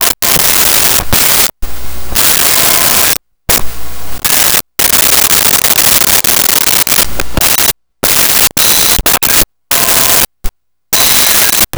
Computer Beeps 03
Computer Beeps 03.wav